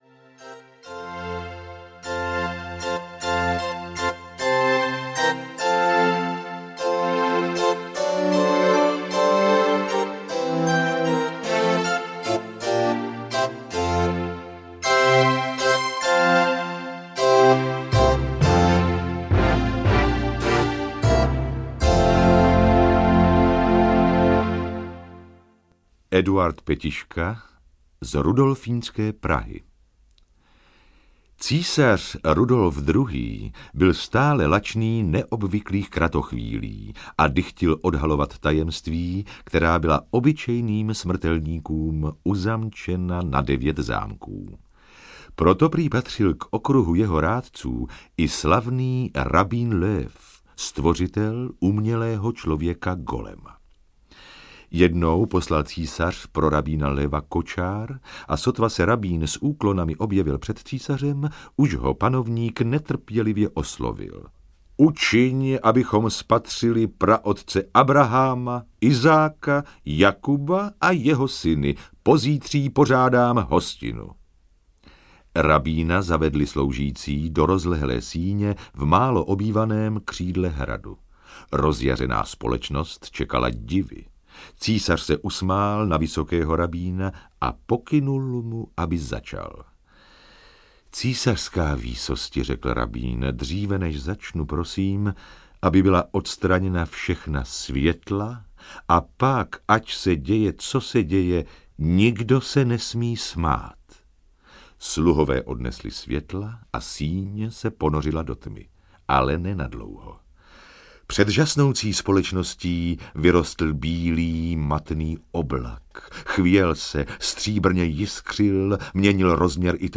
Magická Praha audiokniha
Ukázka z knihy
• InterpretJiří Klem, Jana Hlaváčová, Otakar Brousek st.